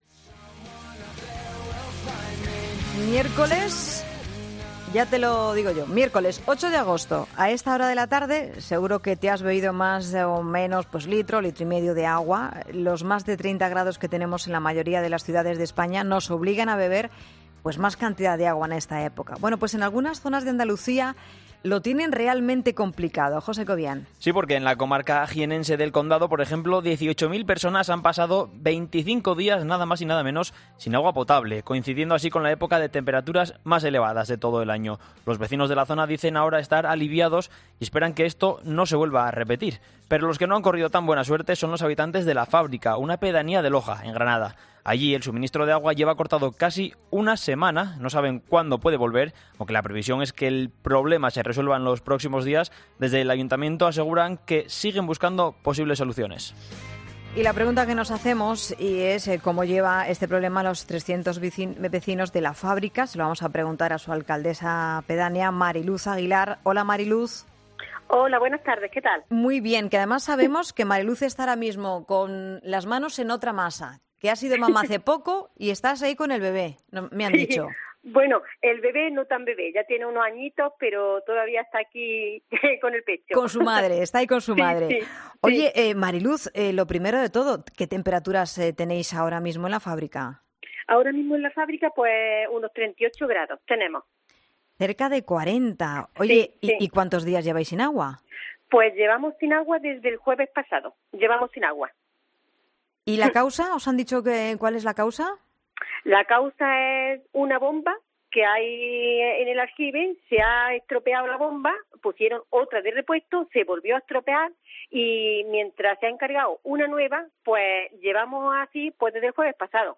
Hablamos en 'La Tarde' con Mari Luz Aguilera, alcaldesa de La Fábrica